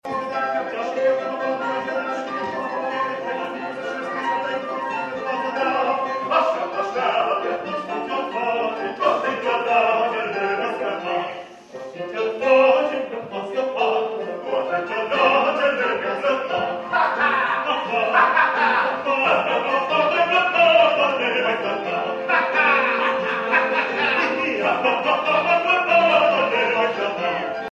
Muzica nu a lipsit din spectacolul-comedie, actorii demonstrând un talent extraordinar şi pe acest plan.
Sala Thalia a răsunat în aplauzele celor prezenţi, cu atât mai mult cu cât intrarea a fost liberă sâmbătă dimineaţa atunci când a fost pusă în scenă piesa.